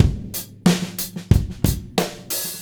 • 122 Bpm 00s Drum Loop F# Key.wav
Free breakbeat sample - kick tuned to the F# note. Loudest frequency: 2114Hz